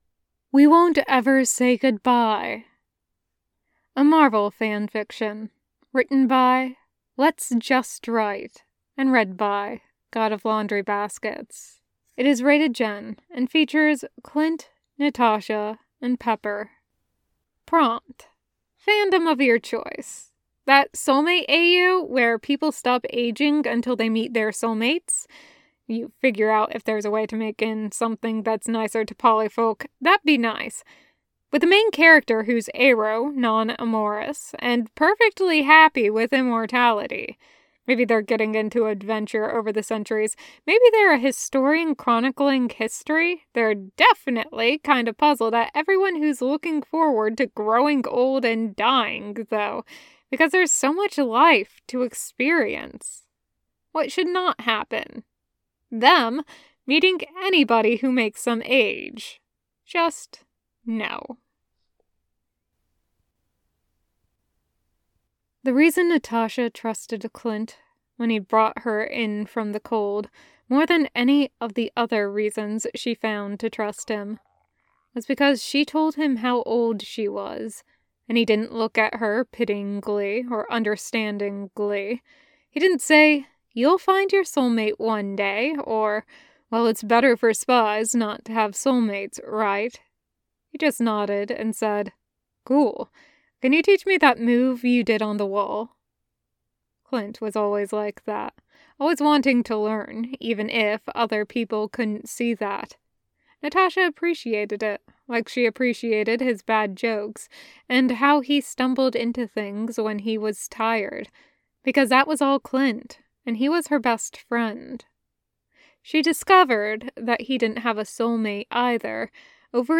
[Podfic] We Won't Ever Say Goodbye